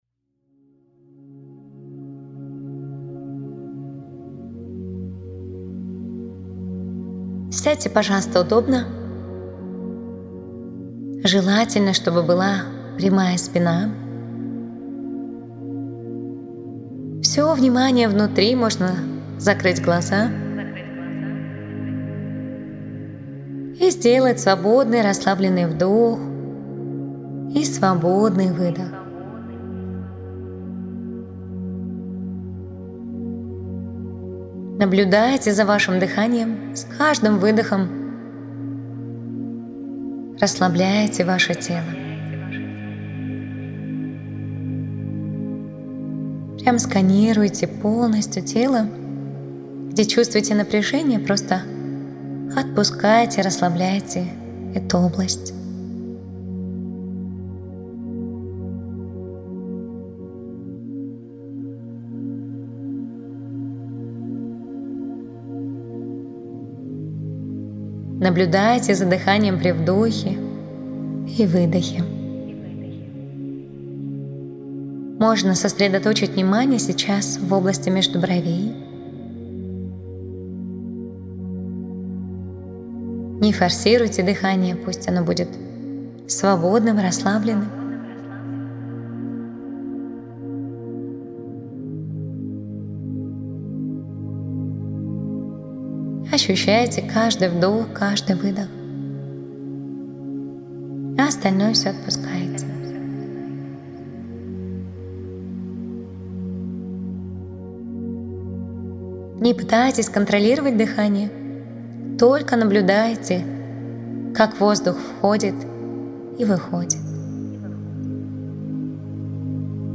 Уникальные аудио уроки и практики по медитации со звуком ОМ
Медитация наблюдения за дыханием с мантрой ОМ
meditaciya-nablyudenie-za-dihaniem-s-mantroi-om-praktika.mp3